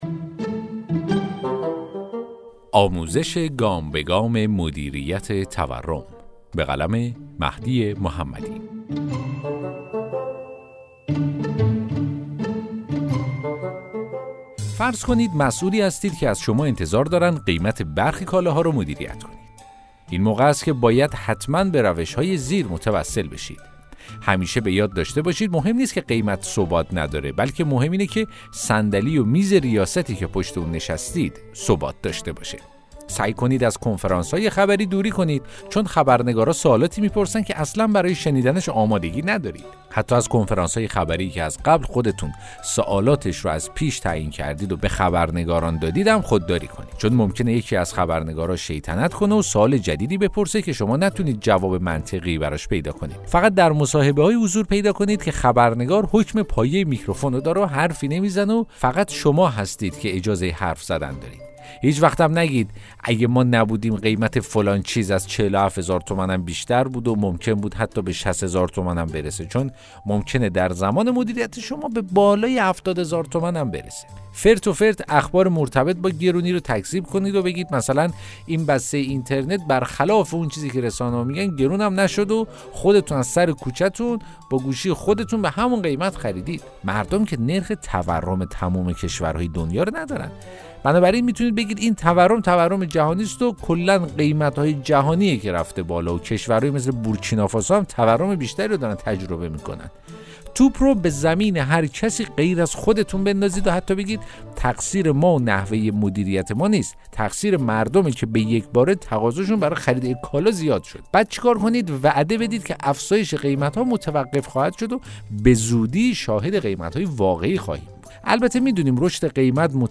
داستان صوتی: آموزش گام به گام مدیریت تورم